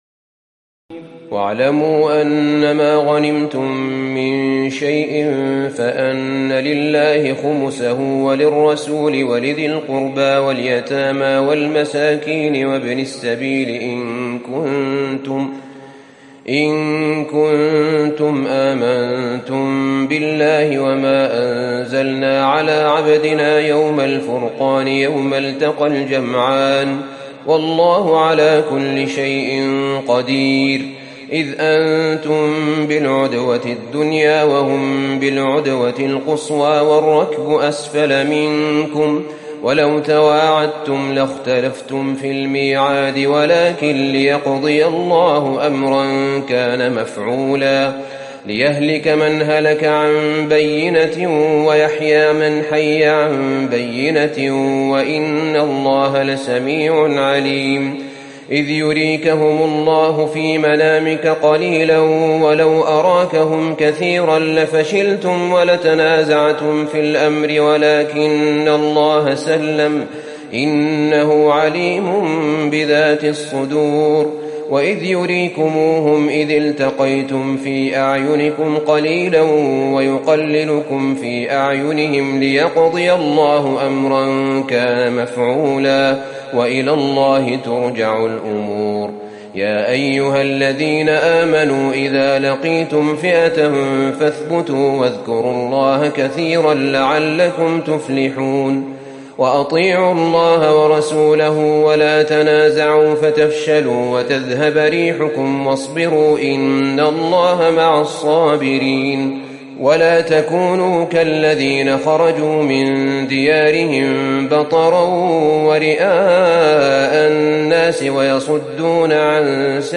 تراويح الليلة العاشرة رمضان 1438هـ من سورتي الأنفال (41-75) و التوبة (1-33) Taraweeh 10 st night Ramadan 1438H from Surah Al-Anfal and At-Tawba > تراويح الحرم النبوي عام 1438 🕌 > التراويح - تلاوات الحرمين